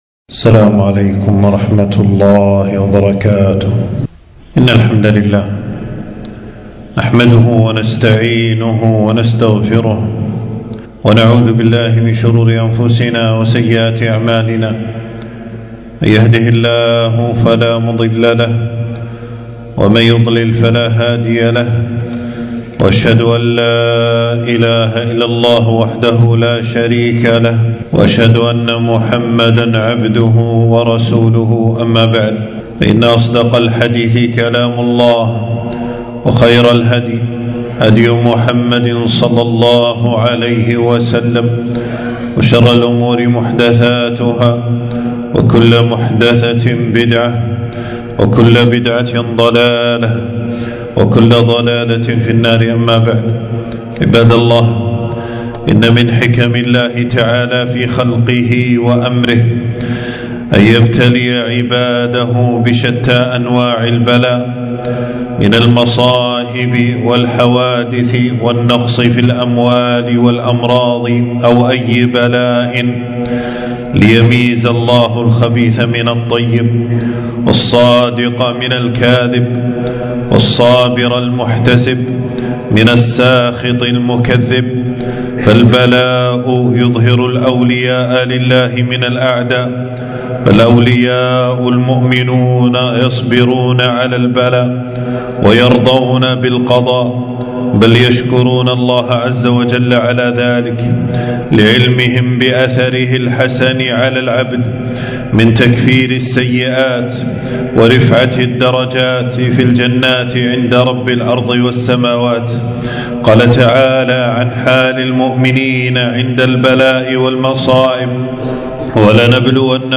حفظه الله تعالى المكان: في مسجد السعيدي بالجهراء